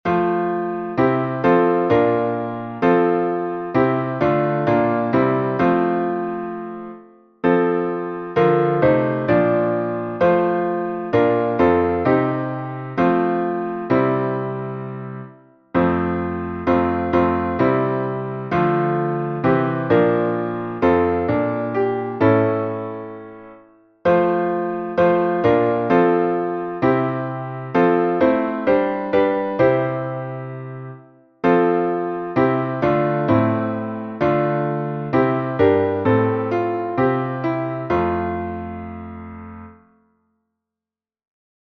salmo_124A_instrumental.mp3